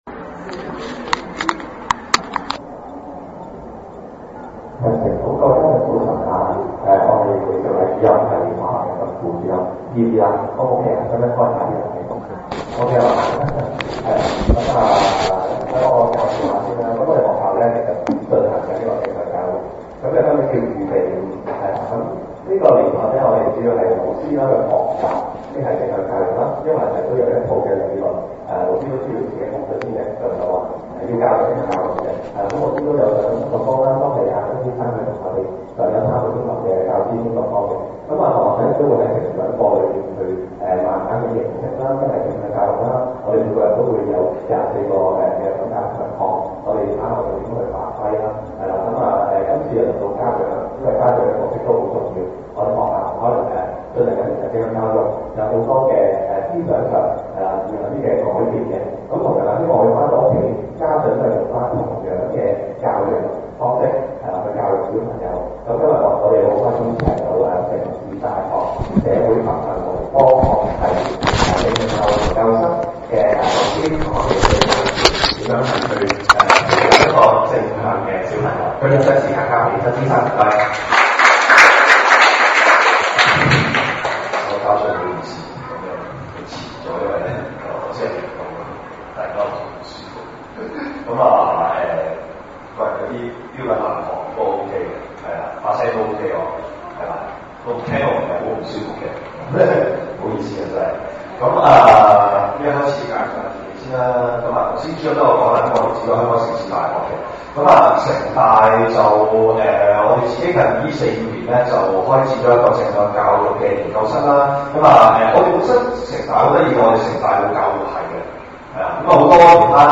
正向教育家長講座.mp3